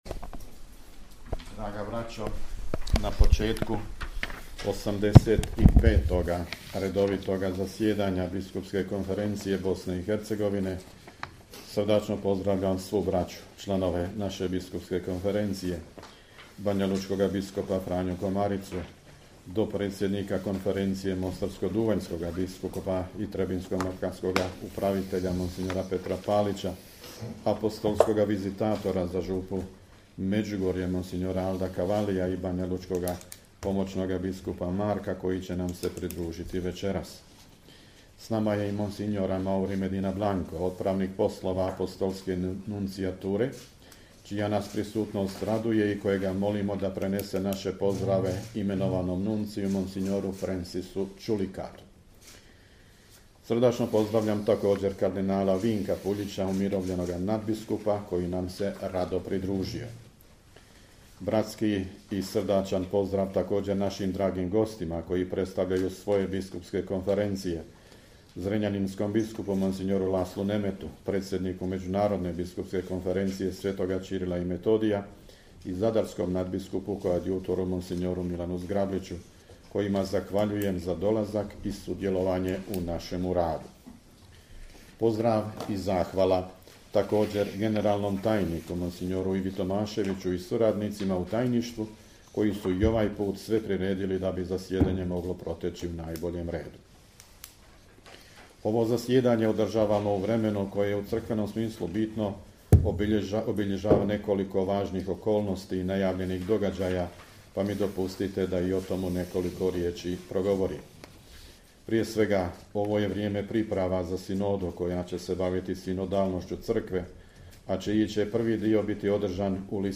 AUDIO: POZDRAV NADBISKUPA VUKŠIĆA NA POČETKU 85. REDOVITOG ZASJEDANJA BISKUPSKE KONFERENCIJE BOSNE I HERCEGOVINE
Biskupska konferencija Bosne i Hercegovine započela je svoje 85. redovito zasjedanje u prostorijama Nadbiskupske rezidencije u Sarajevu, 4. studenog 2022. pod predsjedanjem nadbiskupa metropolita vrhbosanskog i apostolskog upravitelja Vojnog ordinarijata u BiH mons. Tome Vukšića, predsjednika BK BiH. Na početku zasjedanja sve je pozdravio nadbiskup Vukšić, a njegov pozdrav prenosimo u cijelosti.